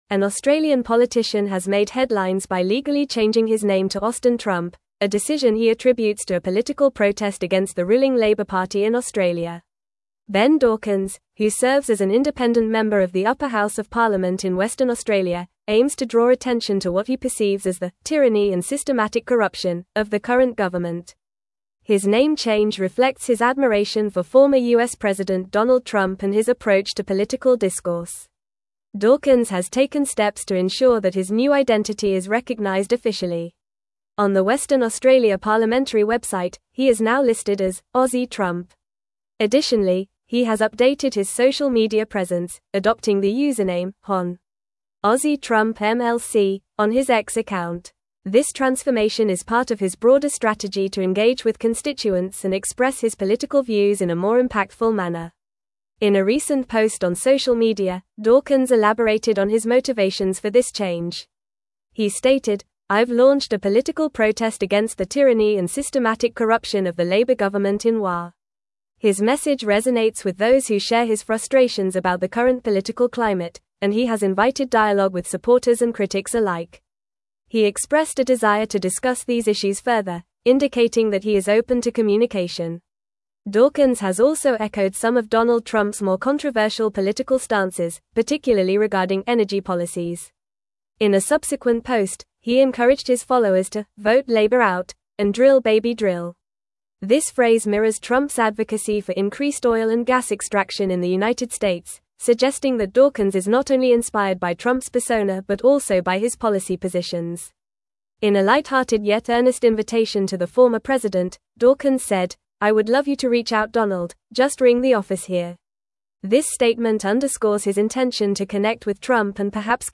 Fast
English-Newsroom-Advanced-FAST-Reading-Australian-Politician-Changes-Name-to-Austin-Trump.mp3